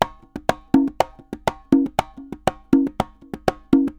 Congas_Merengue 120_1.wav